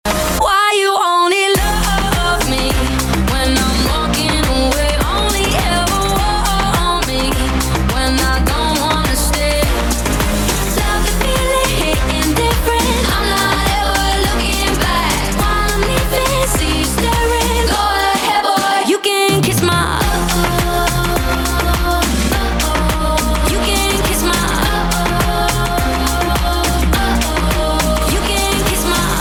energetic vocals
a playful, confident vibe